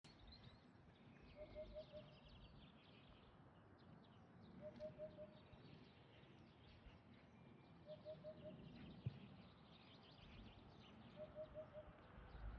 Pupuķis, Upupa epops
StatussBarojas